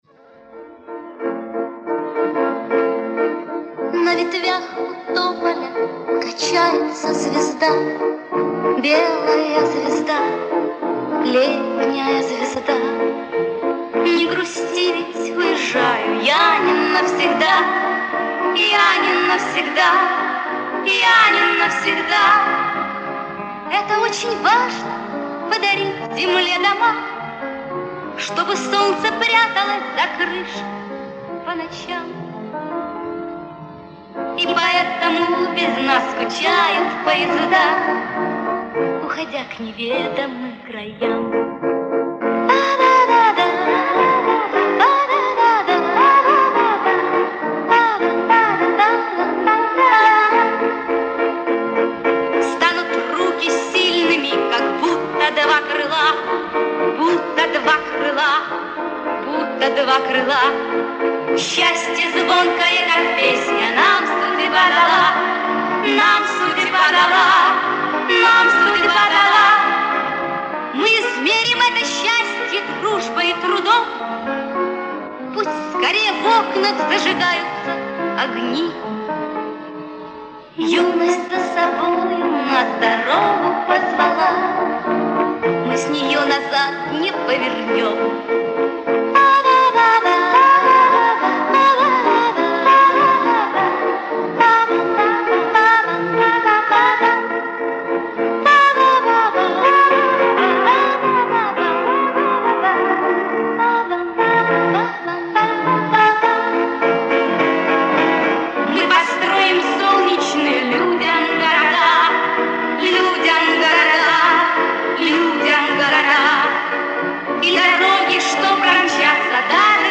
убрал гудение со 2 варианта.